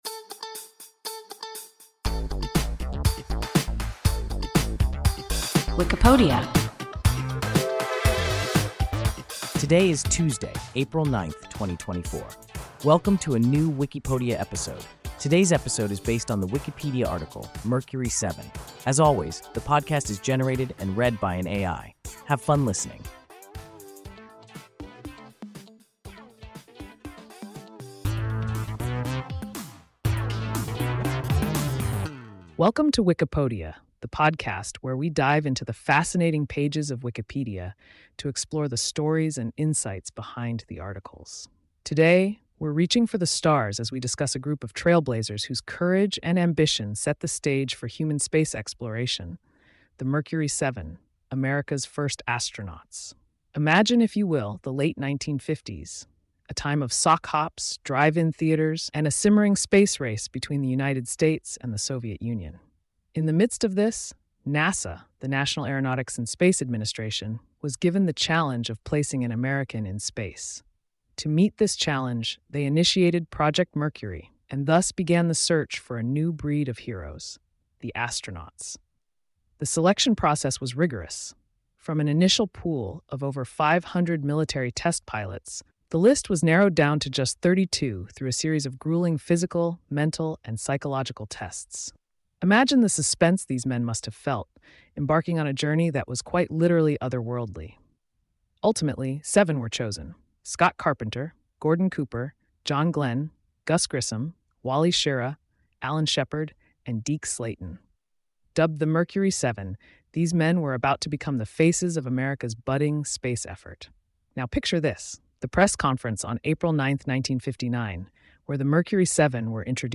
Mercury Seven – WIKIPODIA – ein KI Podcast